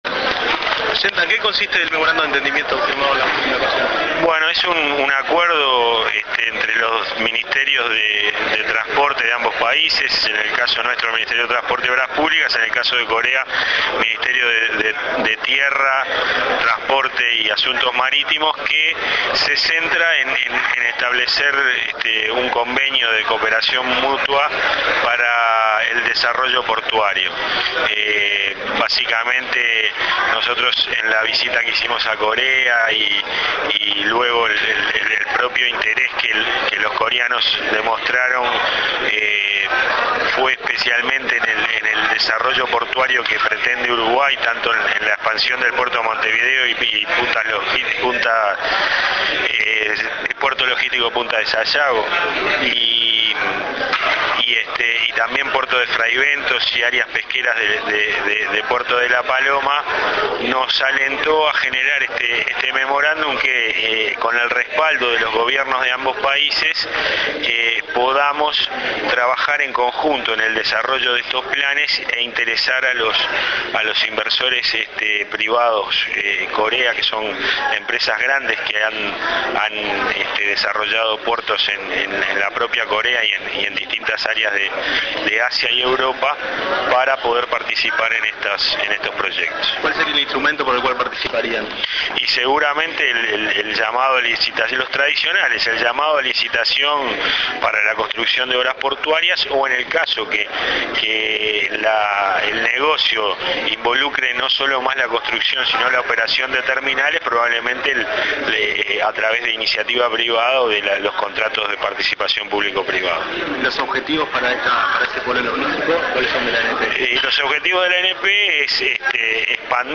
Declaraciones del Subsecretario de Transporte, Pablo Genta, luego de la firma del acuerdo con el Ministerio de Transporte de Corea para cooperación para desarrollo portuario.